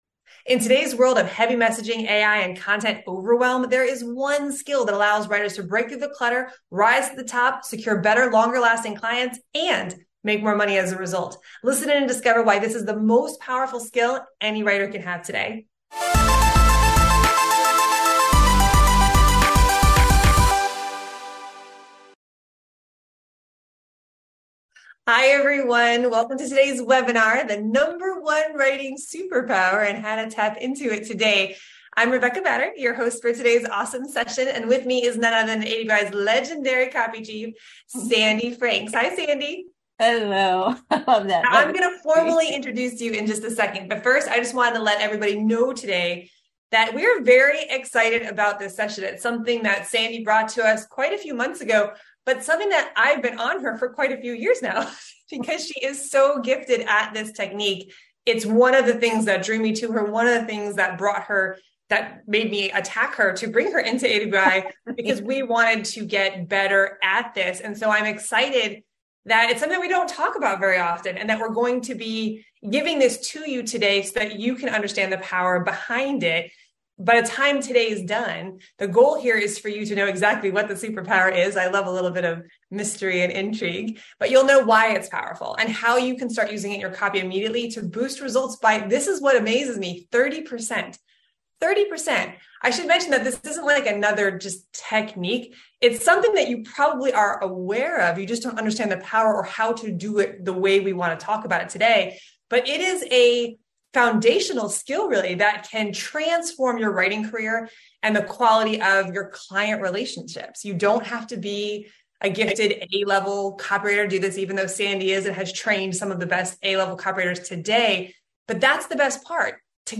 Inside AWAI Webinar and Q&A: The #1 Writing Superpower (And How to Tap Into it Starting Today)
We also held a Q&A with listeners at the end, and shared even more insights!